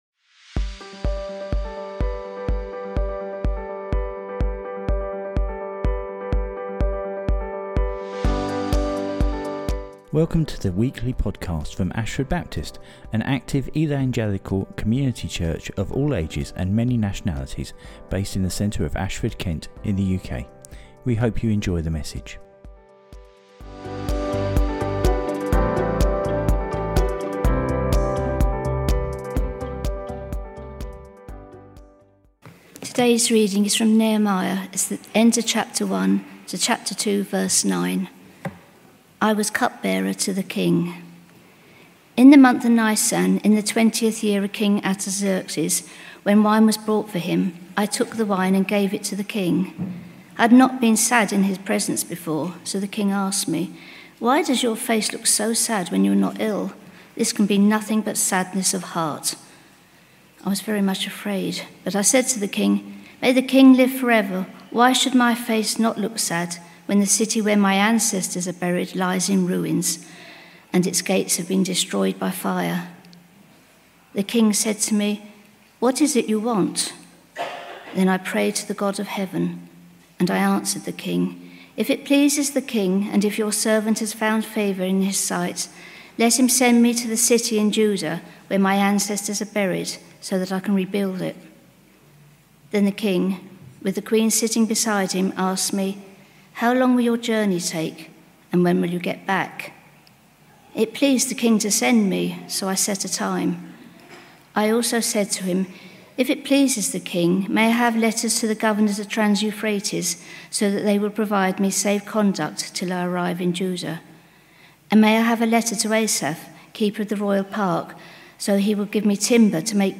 The bible reading is from Nehemiah 1 and 2.